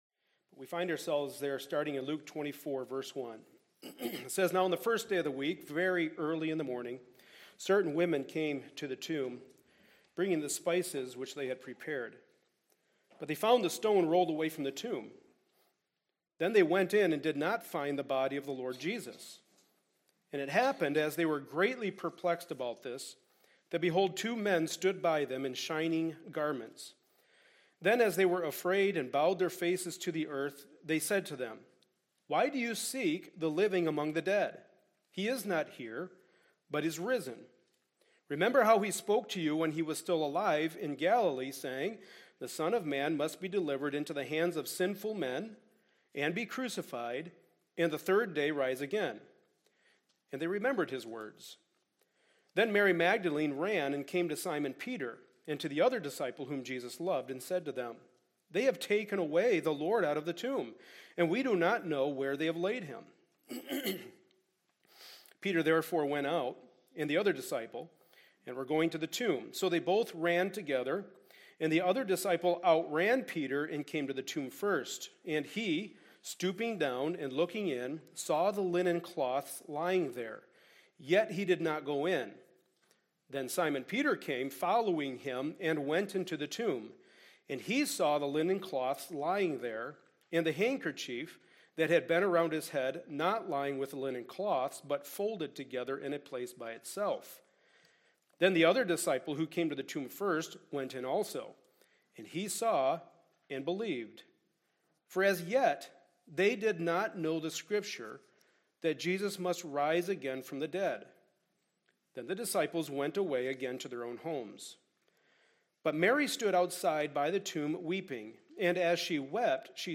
1 Co. 15:1-11 Service Type: Sunday Morning Service On Resurrection Sunday we once again looked closely at the Gospel